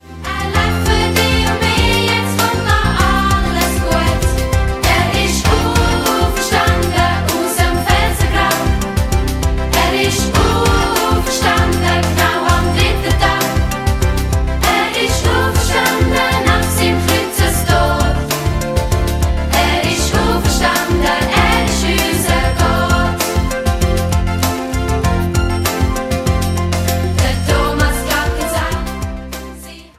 neue und alte Dialektsongs für Kinder